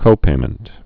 (kōpāmənt)